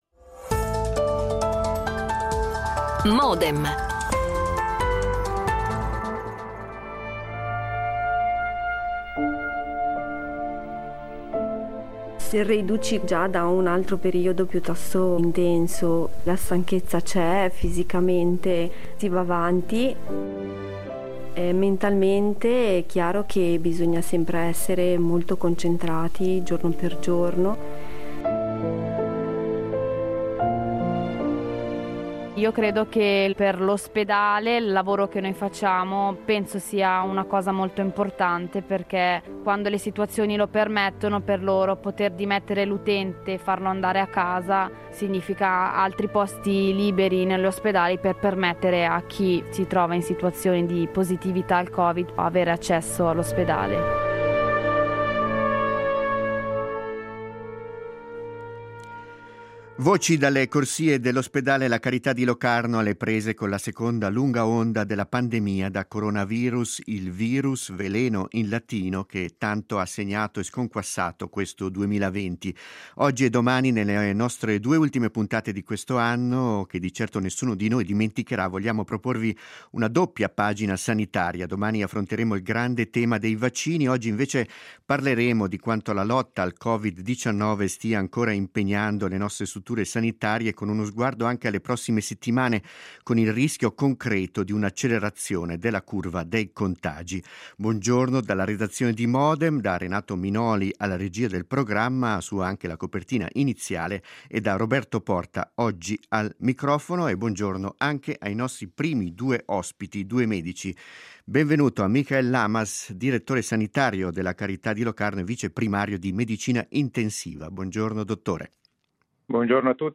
In due reportages proporremo da una parte le testimonianze del personale di cura all'ospedale La Carità di Locarno, dall'altra quelle di alcuni infermieri impegnati nei servizi di cure a domicilio.
L'attualità approfondita, in diretta, tutte le mattine, da lunedì a venerdì